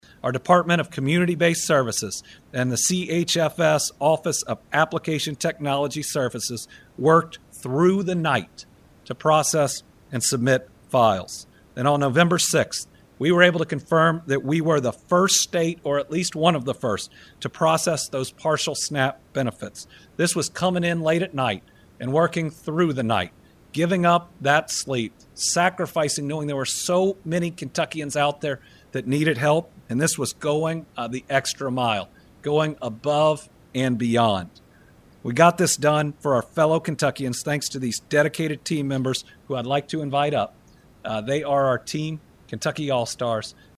Governor Andy Beshear addressed the impact of the recent federal government shutdown and updates on SNAP benefits during his Team Kentucky Update on Thursday.